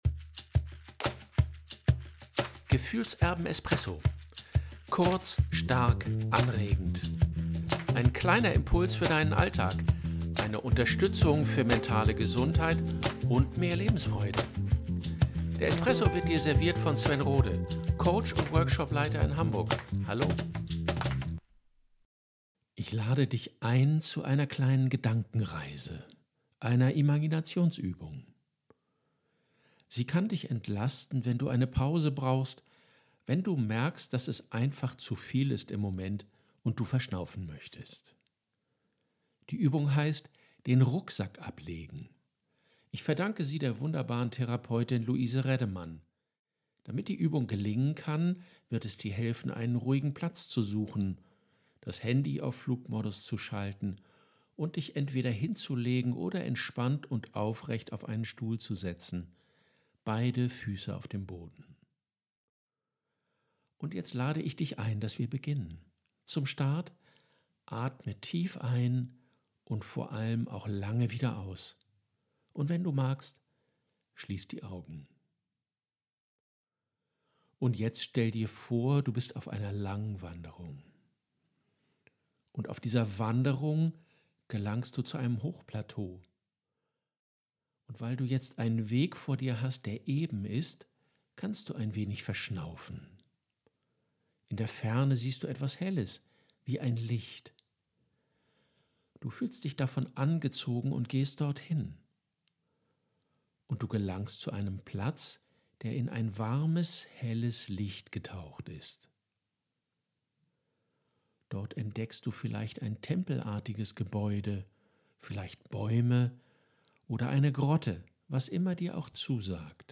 Eine Gedankenreise und Imagination, die dir eine kleine Pause im Alltag verschaffen kann